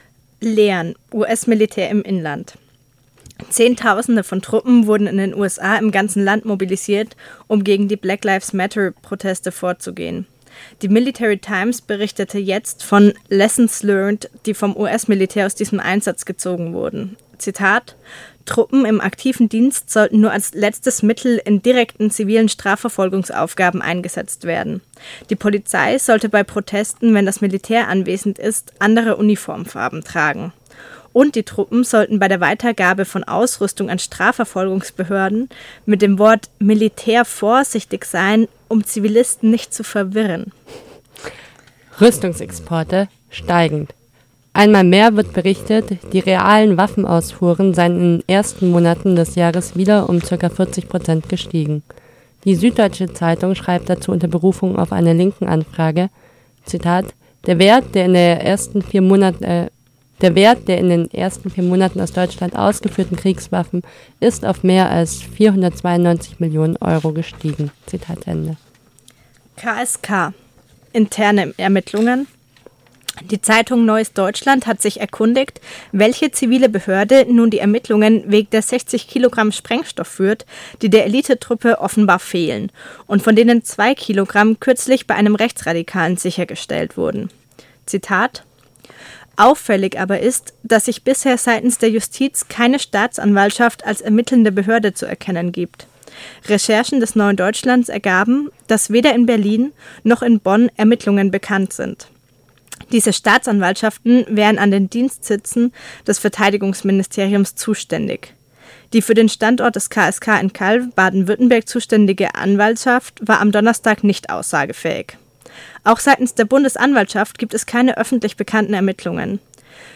IMI Aktuell - Aktuelle Kurznachrichten aus antimilitaristischer Perspektive